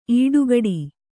♪ īḍugaḍi